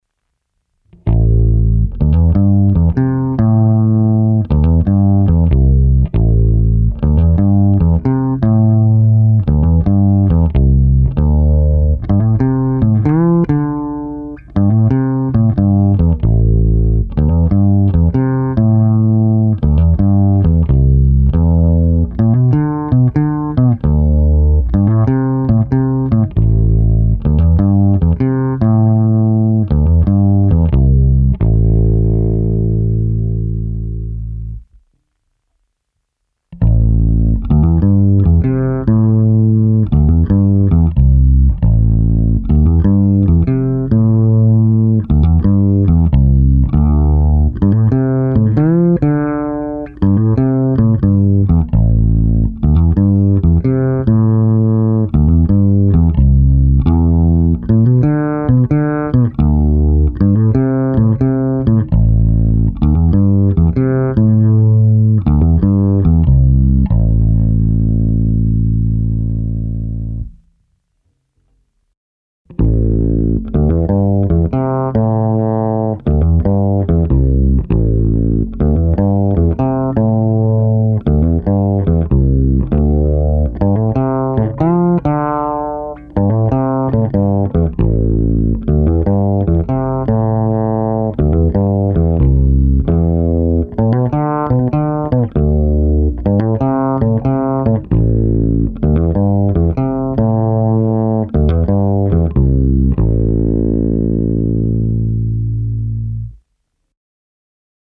Nahrávky v pořadí: krkový snímač - oba snímače - kobylkový snímač. Vše hráno blízko krku. Struny niklové roundwound DR Sunbeams. Plně otevřené tónové clony, filtry nepoužité.
Nahrávka rovnou do zvukovky